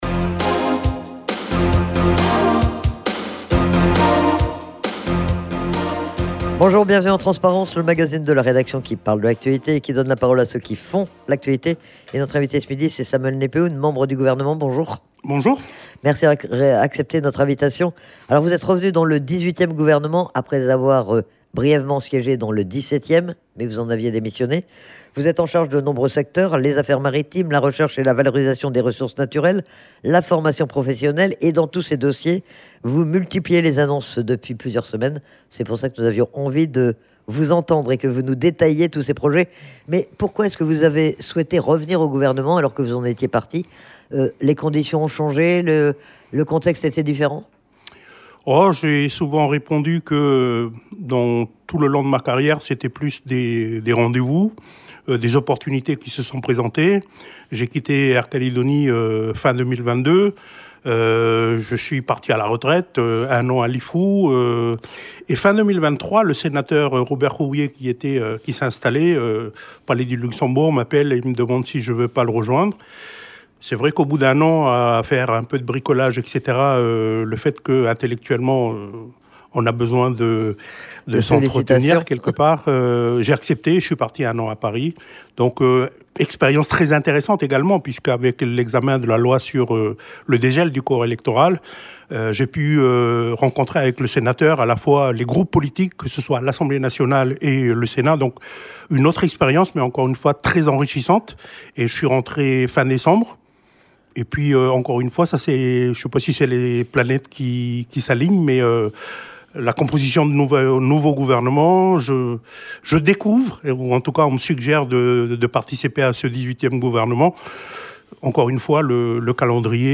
Il est en charge notamment des affaires maritimes, de la recherche et de la valorisation des ressources naturelles mais aussi de la formation professionnelle et il a multiplié les annonces sur ces dossiers depuis plusieurs semaines. Il est interrogé sur les projets qu'il développe concernant ces différents secteurs.